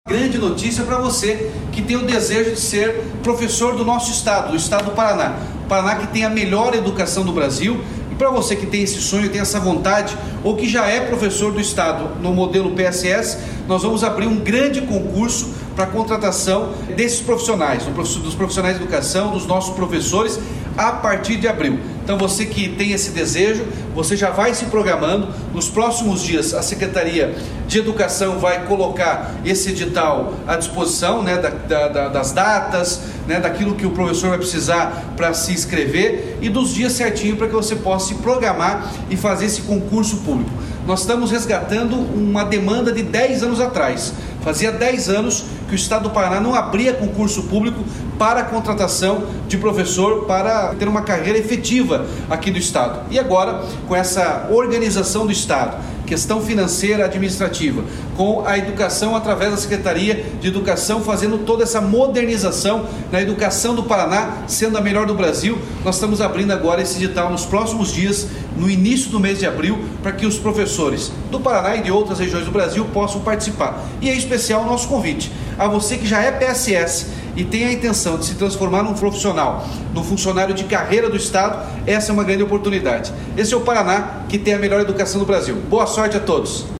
Sonora do governador Ratinho Junior sobre a abertura de concurso para professores na rede estadual